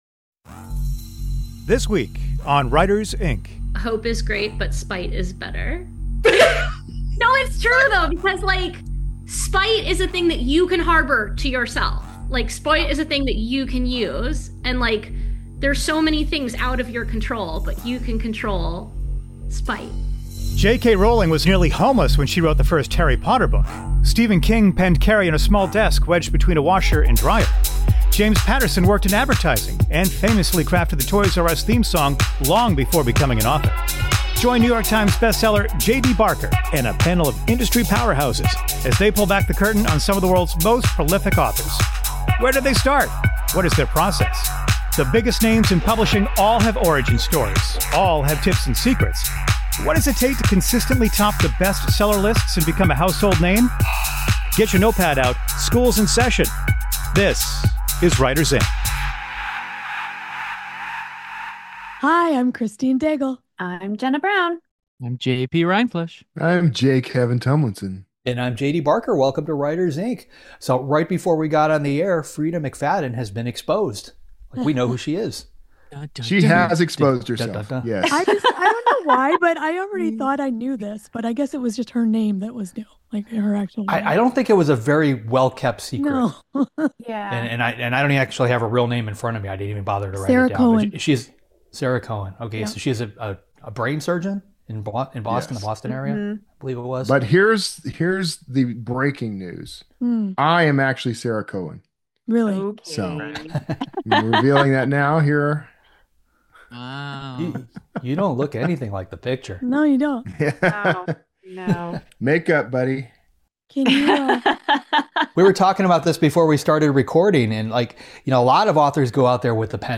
a panel of industry experts